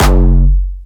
Jumpstyle Kick Solo
2 F#1.wav